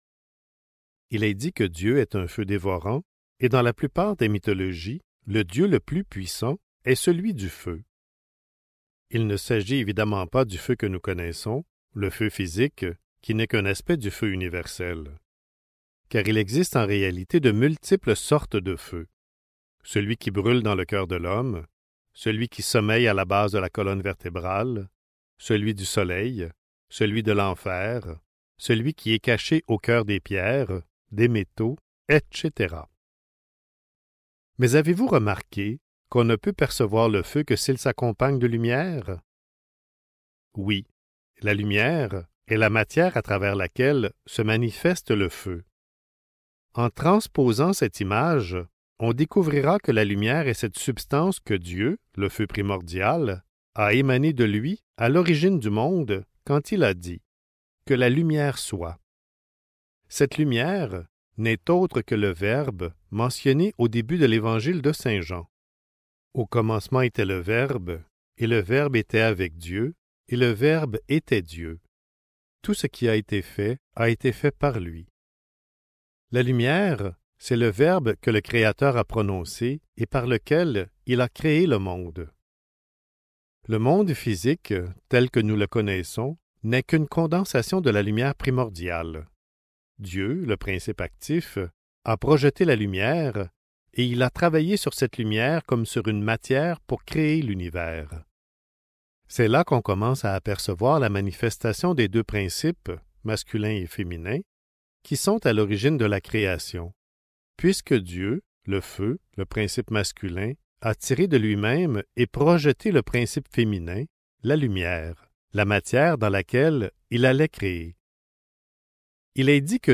La lumière, esprit vivant (Livre audio | CD MP3) | Omraam Mikhaël Aïvanhov